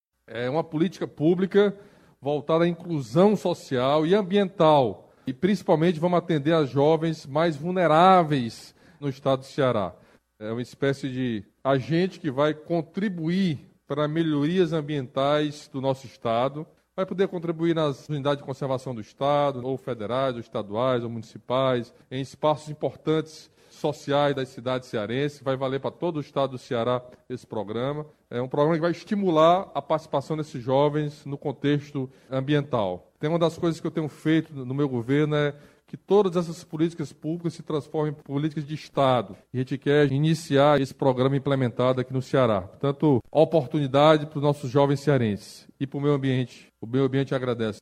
O governador Camilo Santana falou sobre o intuito do programa e destacou o ganho para os jovens e para o meio ambiente.